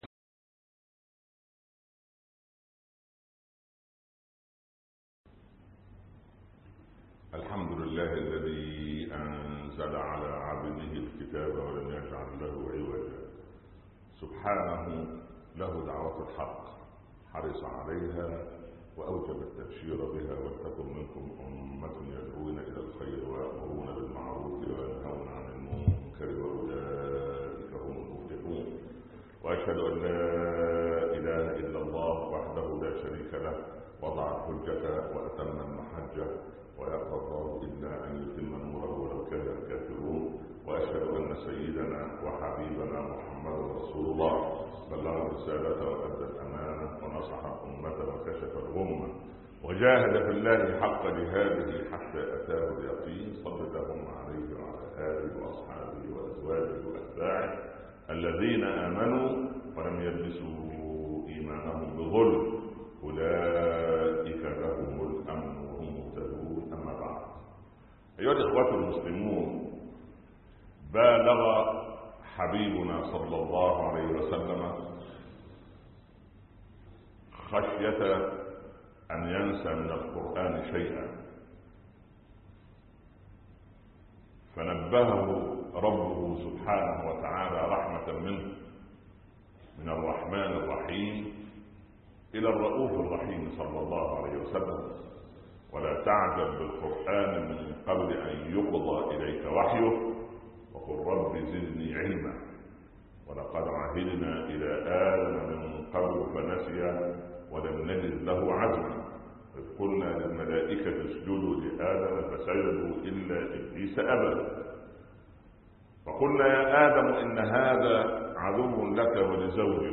الوطن (14/8/2015) خطب الجمعه - الشيخ عمر بن عبدالكافي